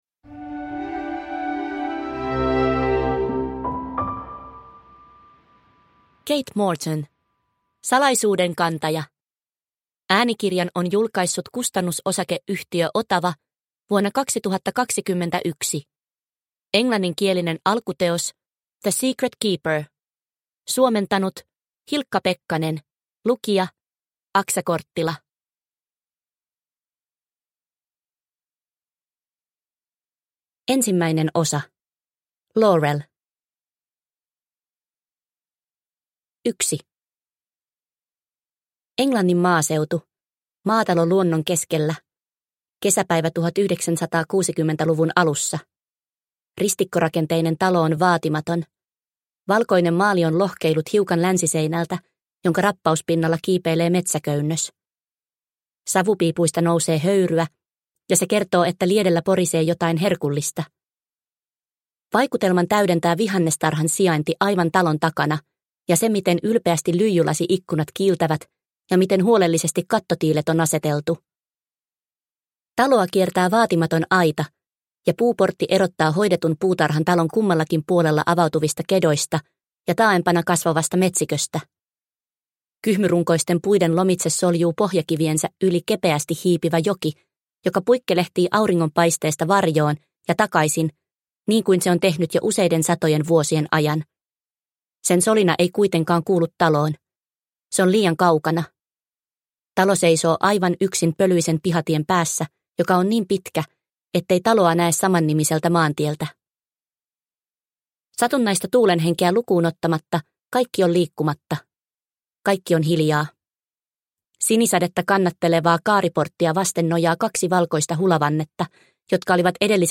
Salaisuuden kantaja – Ljudbok – Laddas ner